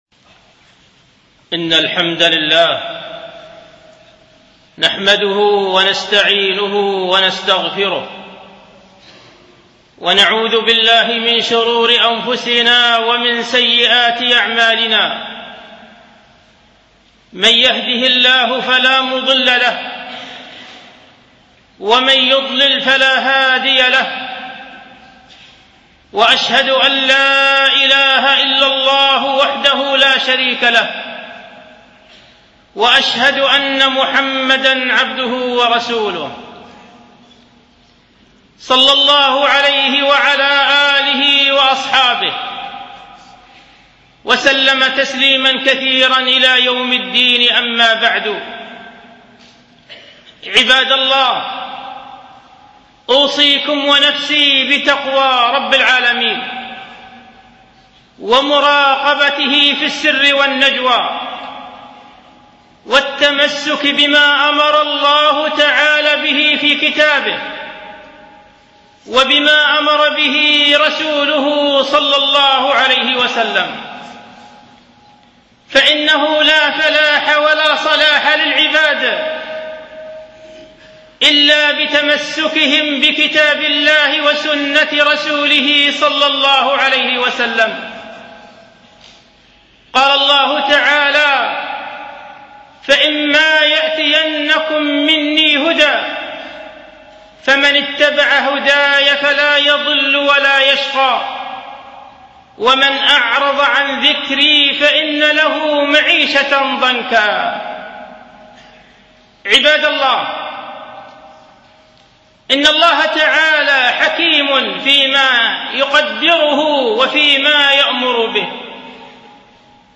خطبة جمعة بعنوان: الأسرة